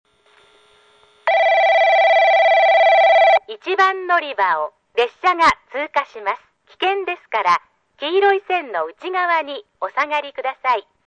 音質：F
１番のりば 通過放送・女性（下り・大分方面） (54KB/11秒)
福岡地区標準放送です。スピーカーの性能もあるのでしょうが、音質はノイズものり、最低を誇っております.....。ピッチは比較的早く鳴ります。